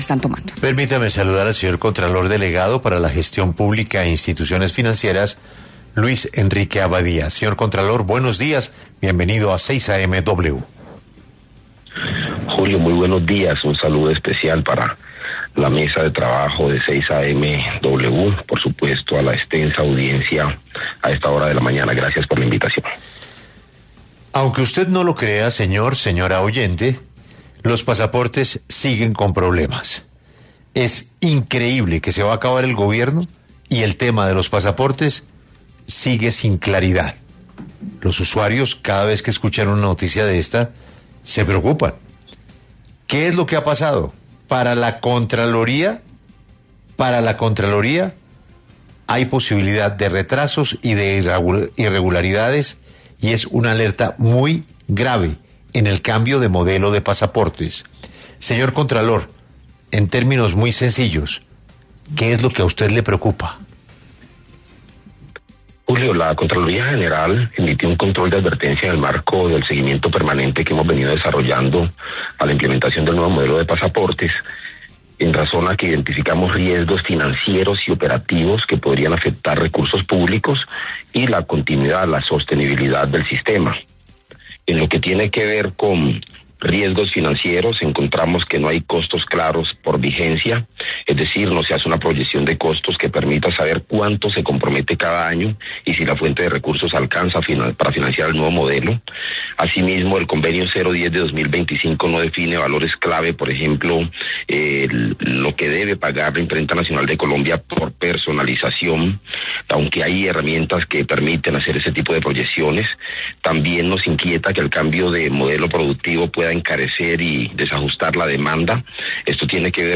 El contralor delegado, Luis Enrique Abadía, pasó por los micrófonos de 6AM W, para hablar sobre el nuevo modelo de pasaportes, asegurando que aunque se espera que esta implementación se dé el 1 de abril, tienen serias inquietudes de que esto pueda darse para dicha fecha.